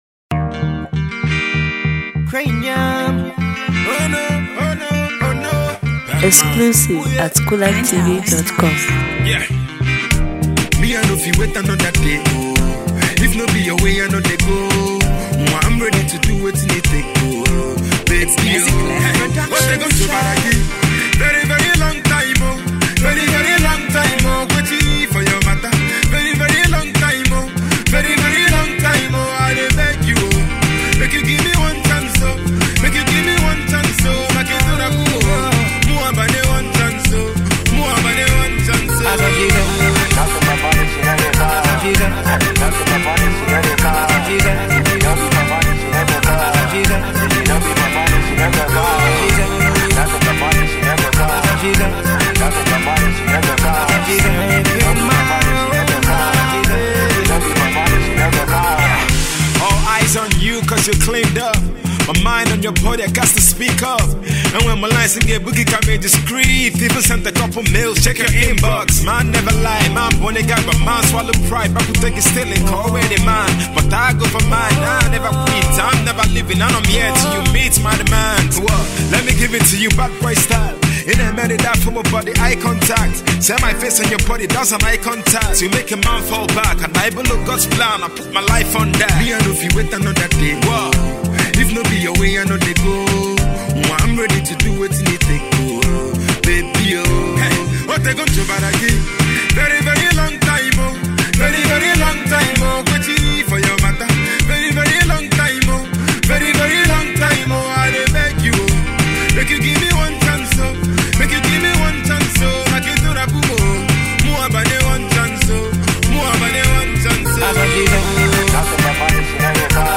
Dancehall rhythm and smooth vibes.